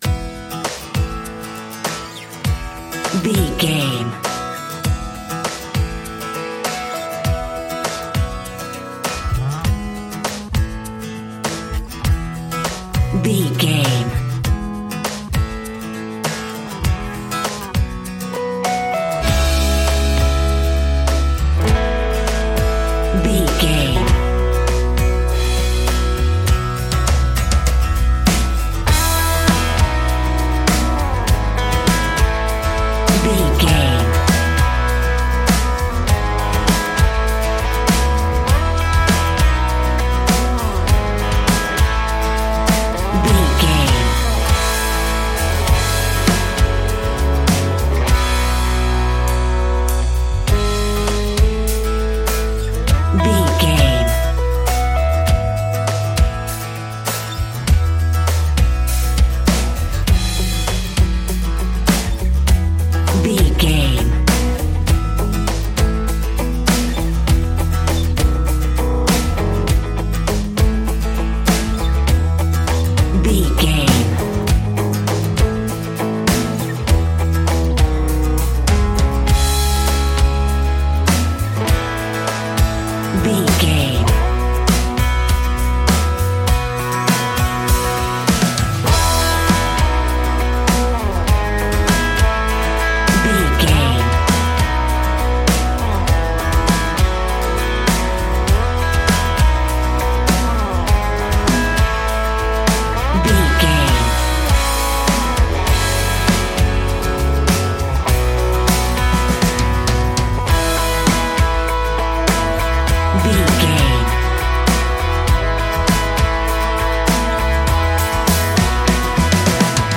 Aeolian/Minor
electric guitar
acoustic guitar
bass guitar
drums